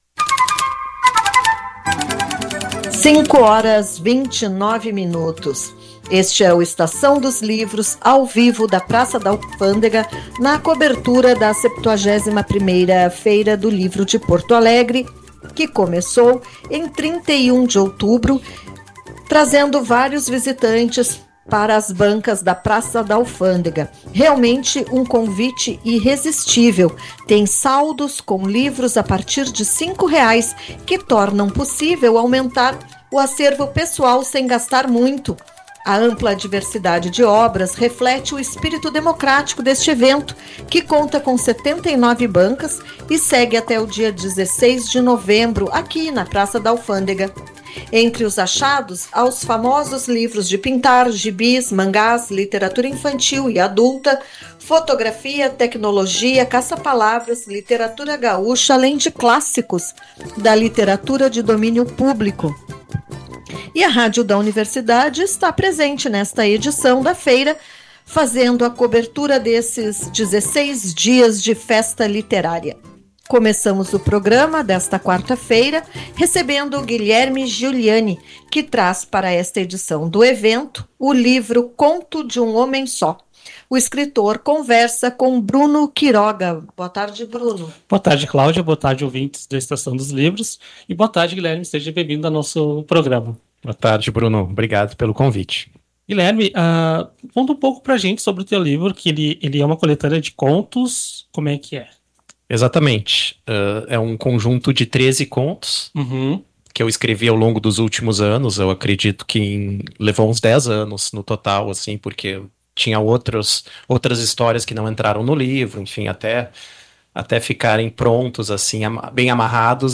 ESTAÇÃO DOS LIVROS AO-VIVO 12-11-25.mp3